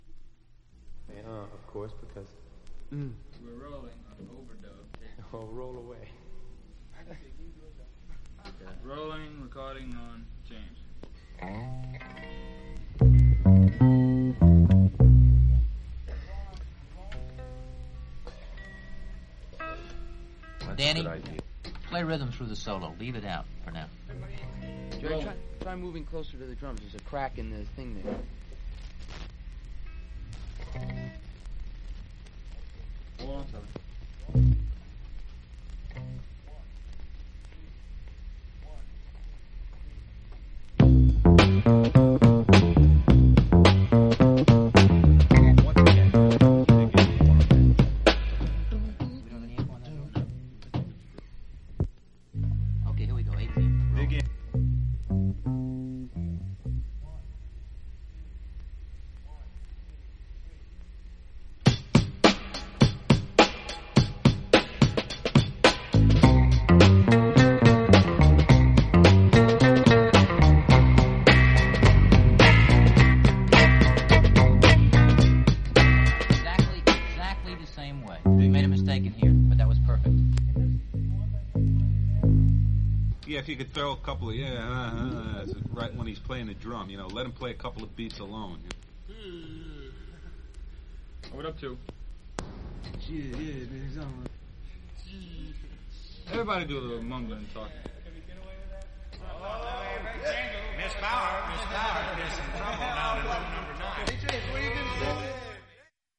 盤面薄いスリキズが少しだけありますが音に影響なく綺麗です。
実際のレコードからのサンプル↓ 試聴はこちら： サンプル≪mp3≫